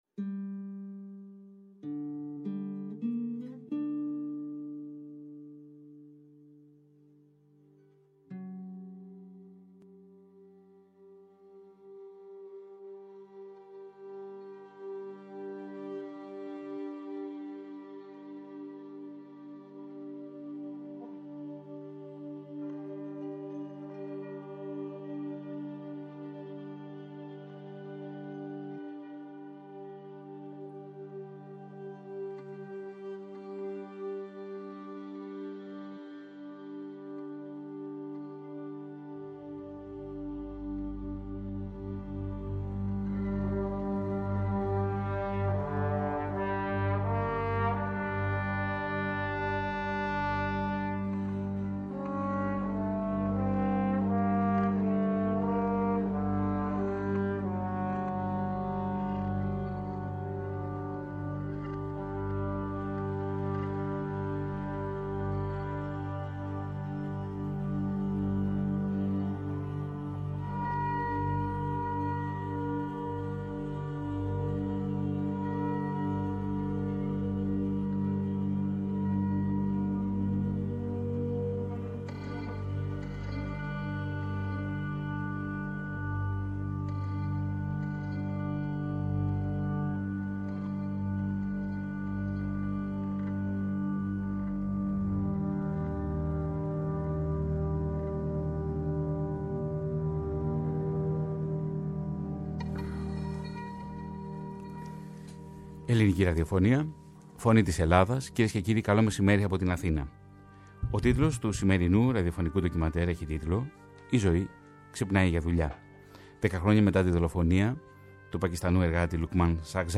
ραδιοφωνικό ντοκιμαντέρ
Στο ντοκιμαντέρ ακούγονται μοναδικά τεκμήρια